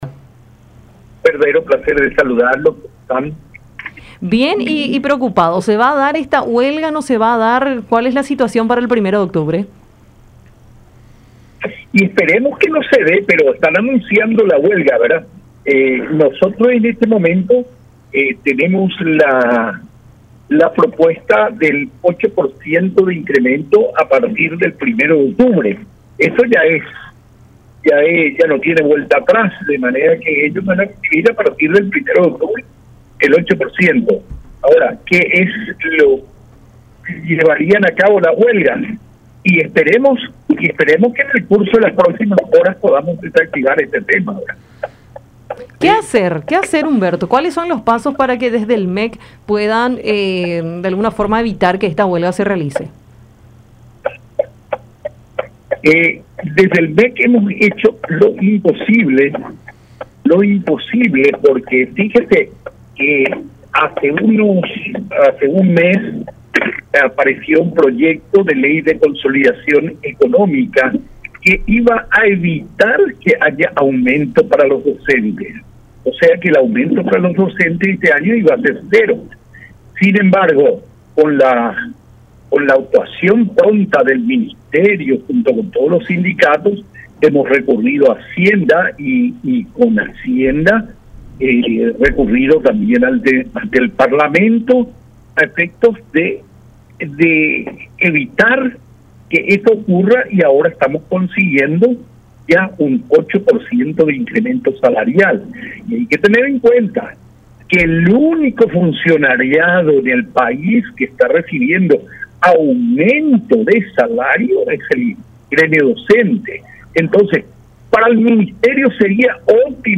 charla con Enfoque 800 a través de La Unión